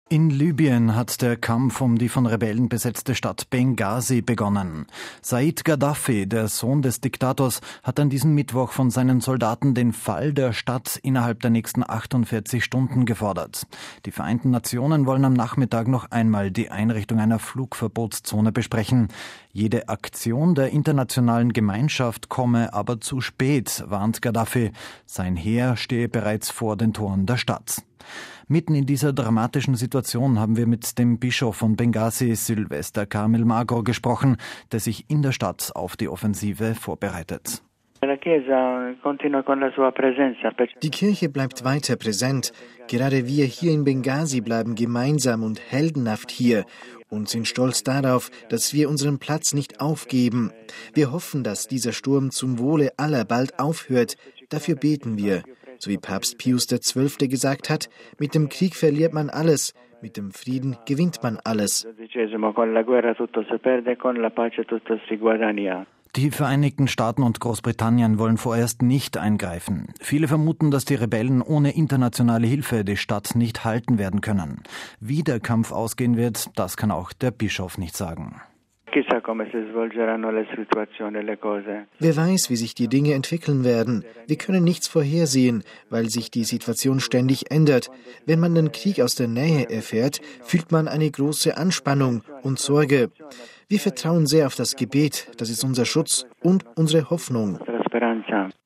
Mitten in dieser dramatischen Situation haben wir mit dem Bischof von Benghasi, Sylvester Carmel Magro, gesprochen, der sich in der Stadt auf die Offensive vorbereitet.